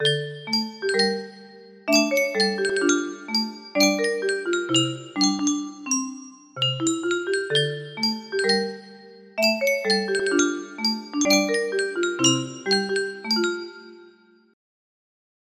Adapted for 30 notes